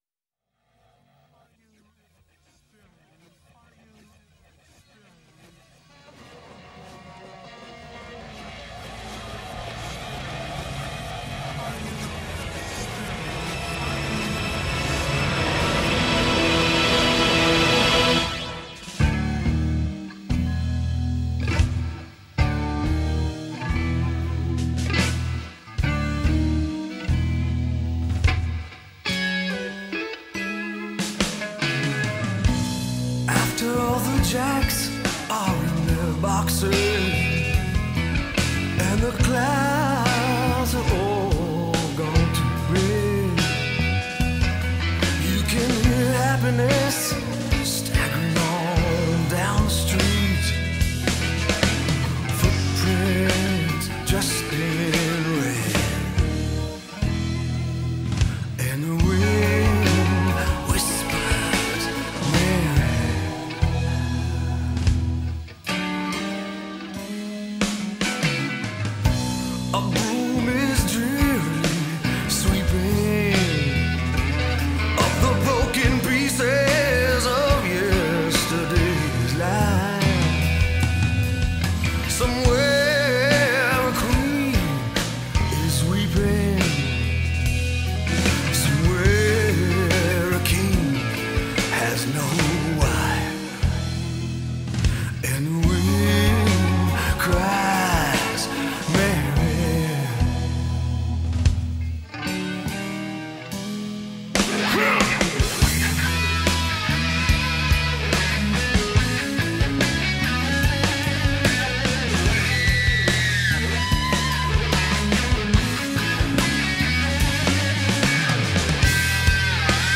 Hard Rock, Blues Rock